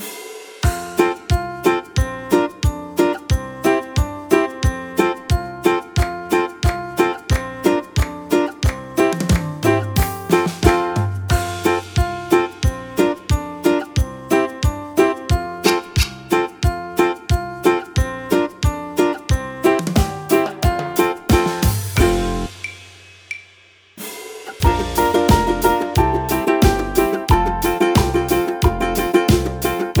utwór w wersji wokalnej i instrumentalnej